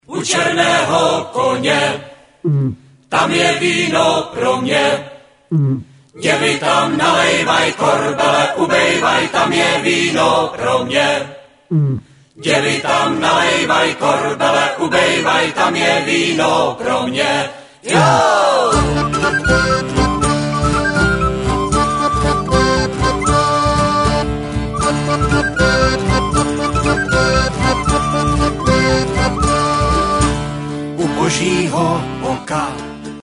kytary, zpěv, mandolína, mandola
flétna, klávesové nástr, zpěv, krumhorn
kontrabas, akordeon, zpěv
housle, zpěv